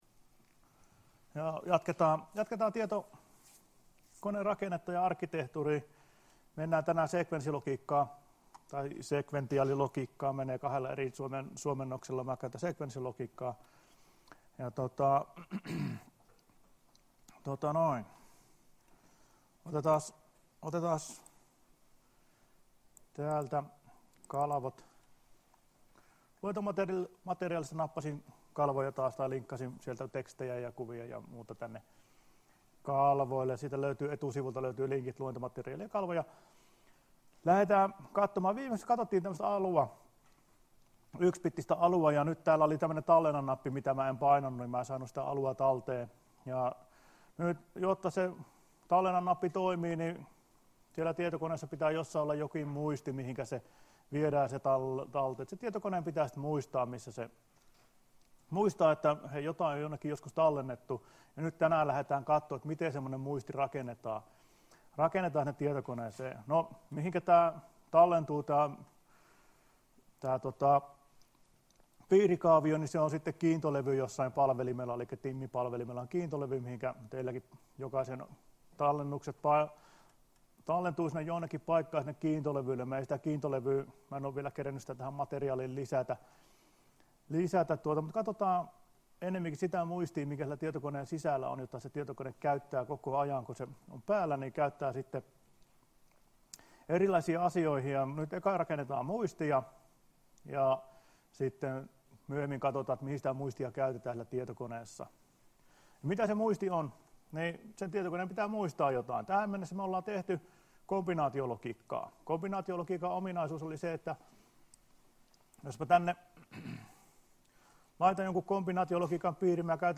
Luento 22.11.2017 — Moniviestin